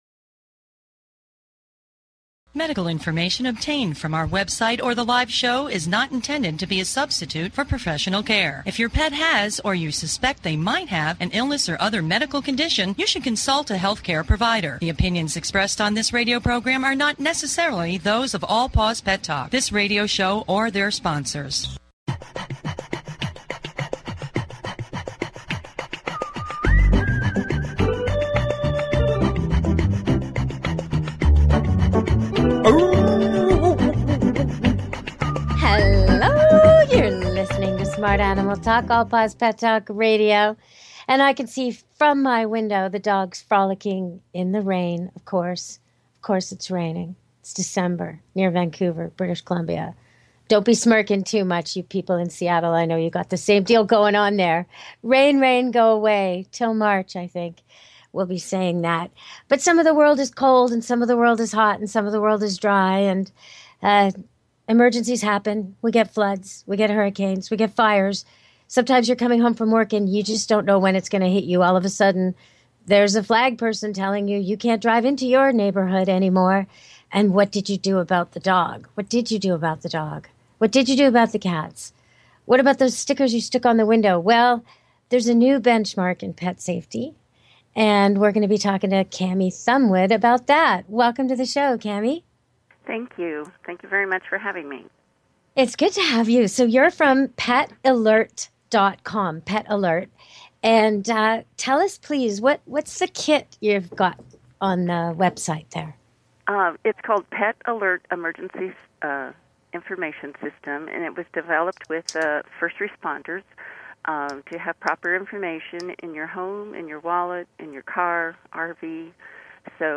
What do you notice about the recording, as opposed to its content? We give listeners the opportunity to speak with animal experts one on one.